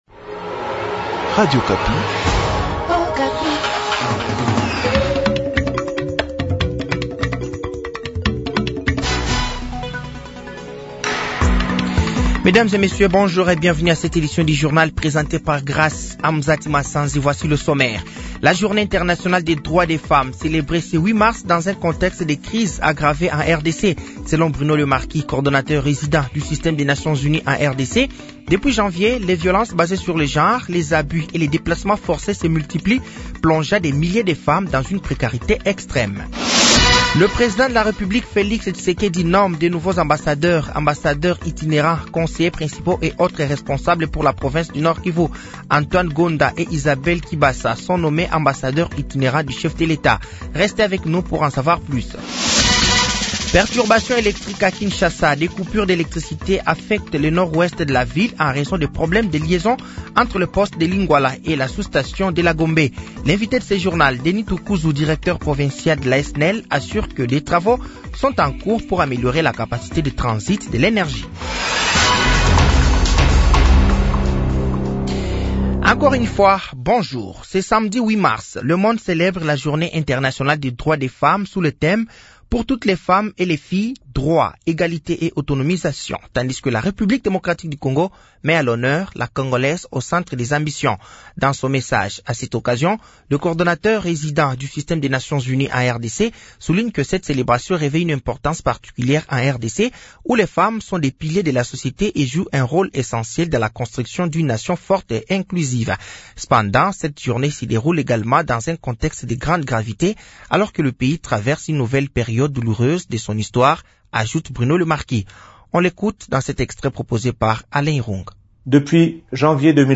Journal français de 15h de ce samedi 8 mars 2025